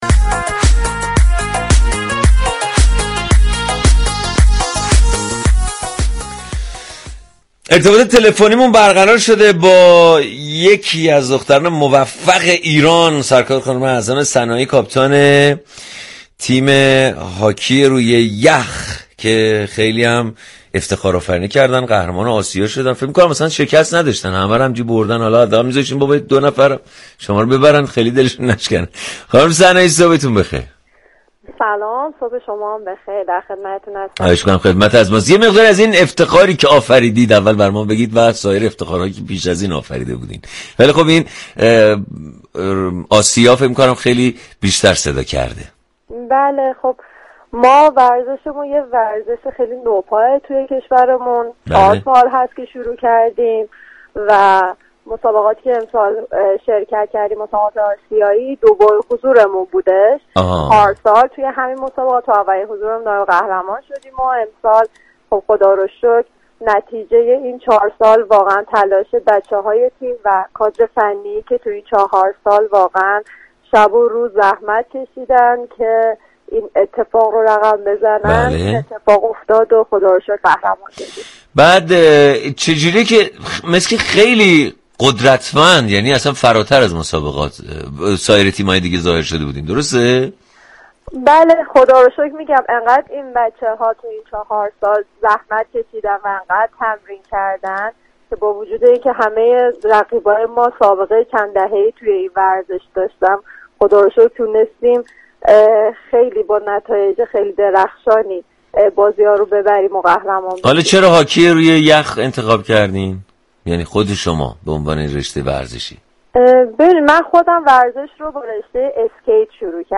به گزارش روابط عمومی رادیوصبا، «صبح صبا» برنامه صبحگاهی این شبكه رادیوی است كه در فضایی شاد مخاطبان را در جریان رویداد ها و اتفاقات روز جامعه قرار می دهد و هر روز با نگاهی طنز به بیان موضوعات فرهنگی، اجتماعی و اقتصادی جامعه می پردازد.